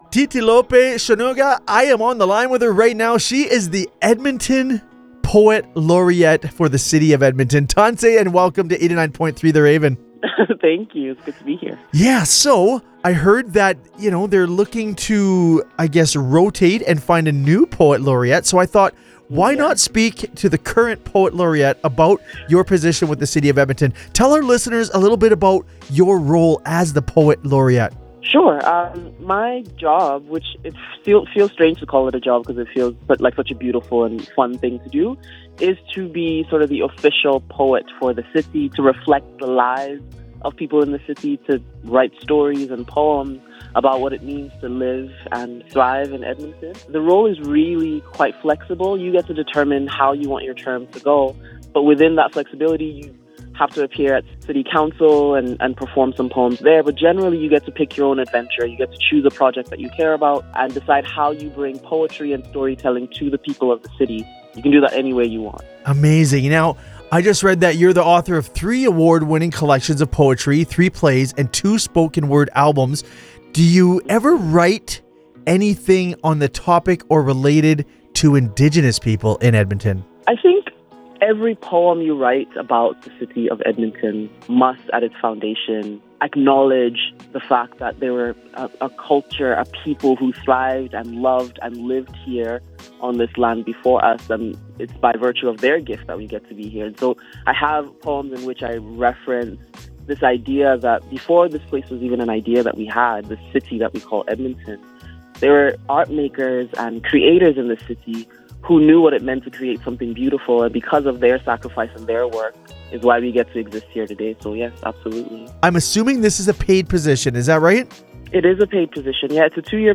chats with the current Laureate Titilope Sonuga about the position